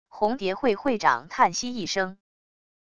红蝶会会长叹息一声wav音频生成系统WAV Audio Player